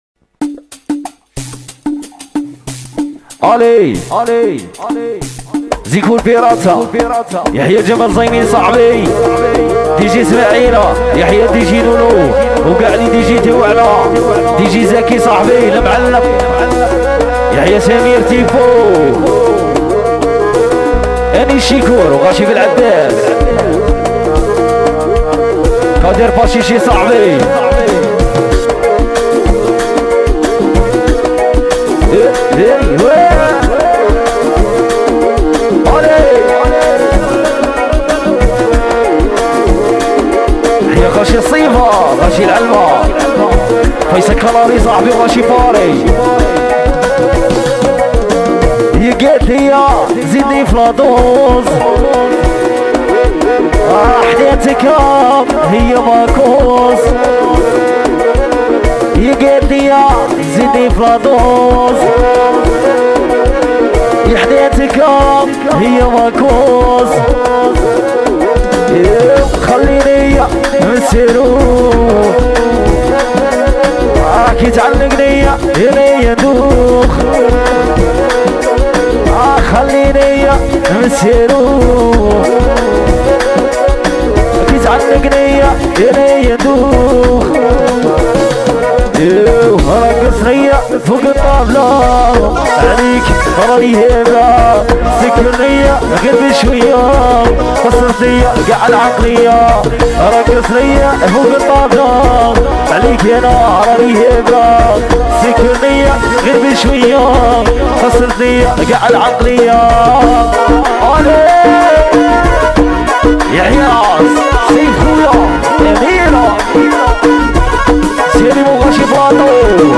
" اغاني راي جزائري "